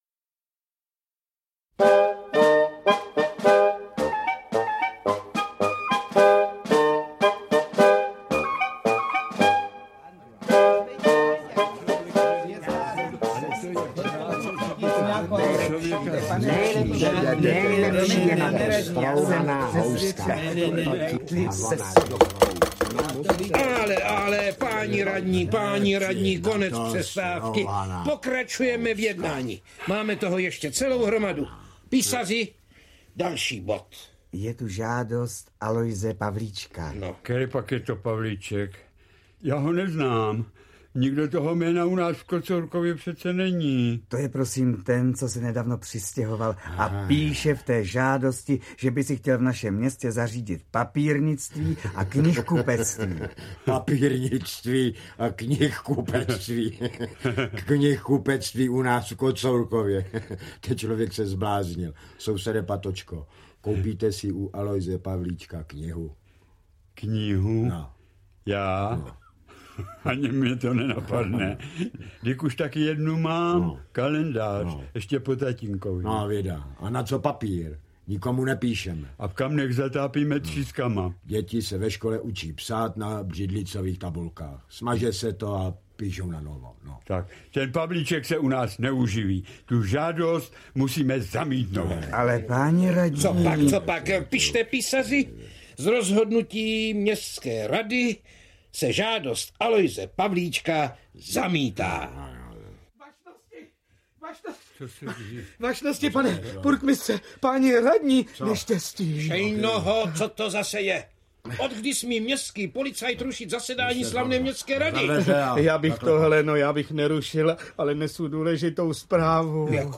• Čte: Josef Beyvl, Vlastimil Hašek, Jan…